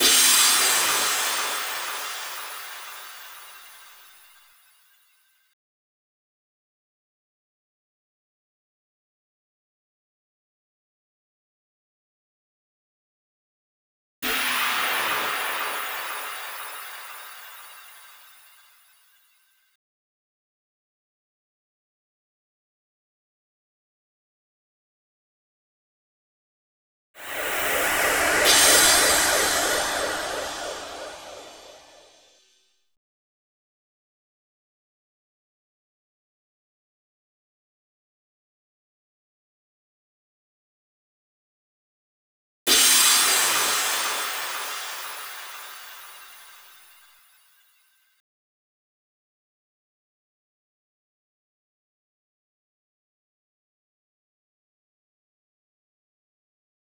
TI CK7 135 Sfx.wav